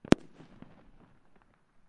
爆炸 " 007烟花
Tag: 大声 臂架 轰隆 烟花 爆竹 爆炸